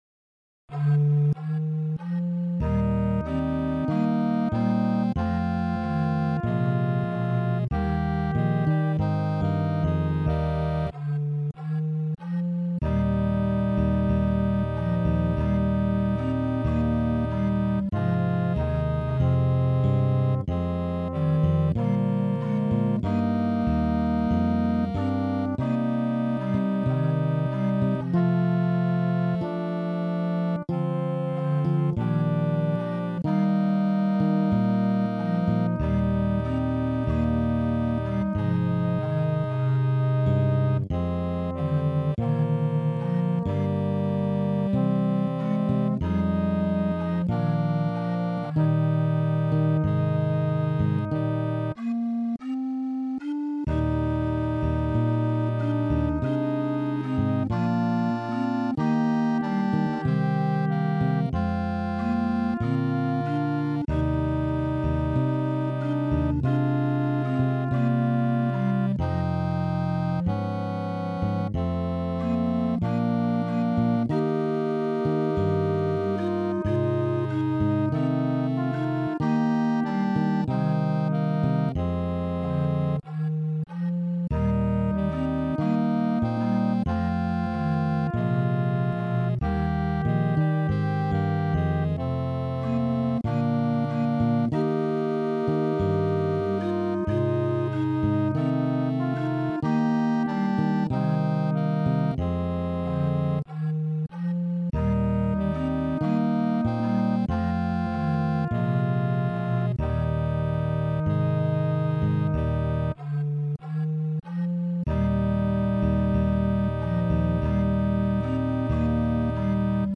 TTBB + solo (5 voix égales d'hommes) ; Partition complète.
Solistes : Bariton (1 soliste(s))
Tonalité : mi bémol majeur